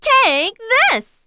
Juno-Vox_Skill1.wav